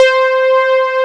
MOOG C6.wav